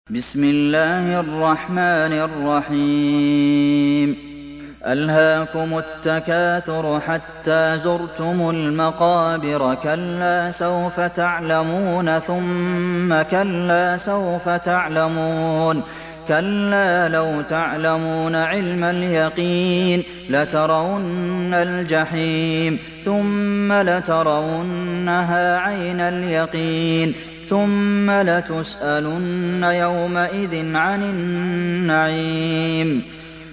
Moratal